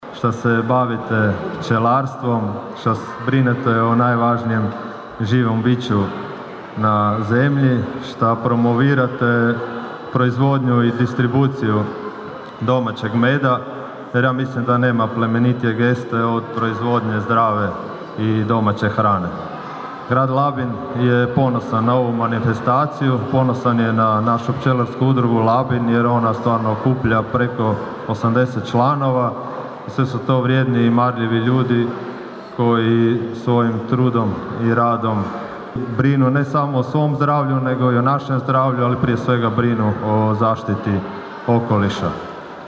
Otvarajući manifestaciju gradonačelnik Labina Donald Blašković zahvalio je pčelarima: (